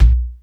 Kick_34.wav